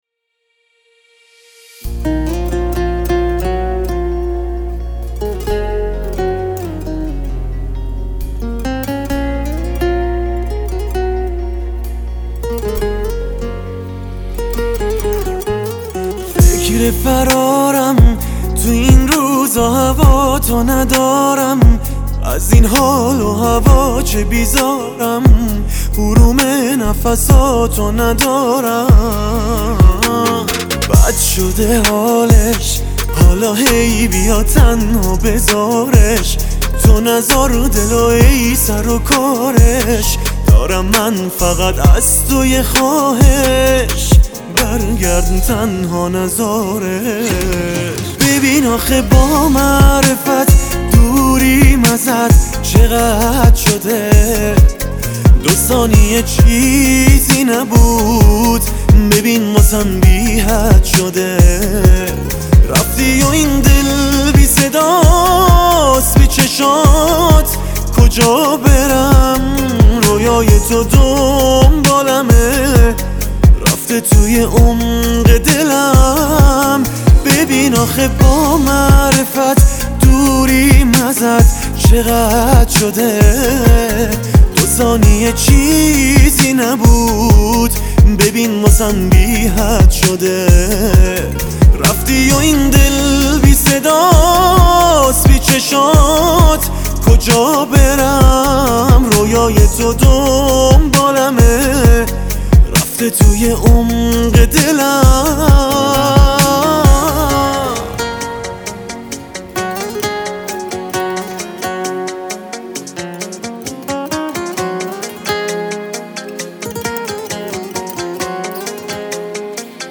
آهنگ دلنشین و زیبا و البته آرامشبخش